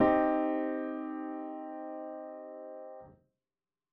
C Minor Triad